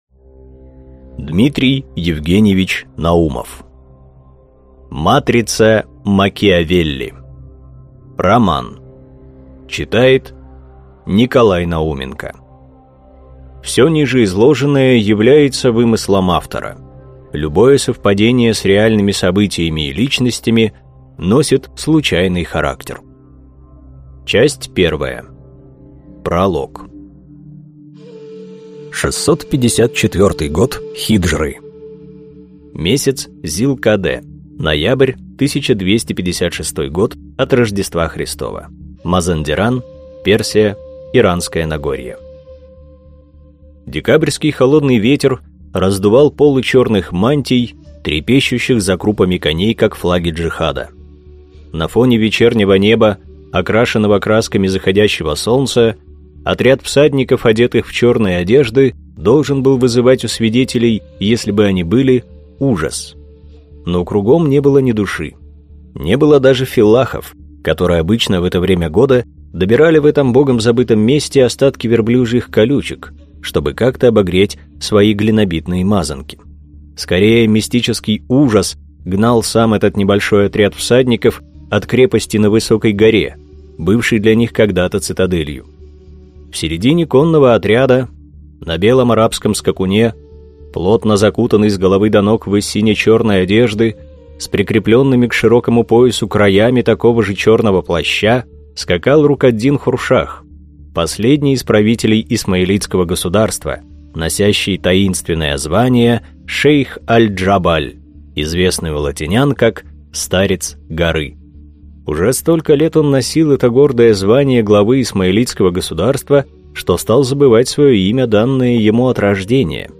Аудиокнига Матрица Макиавелли | Библиотека аудиокниг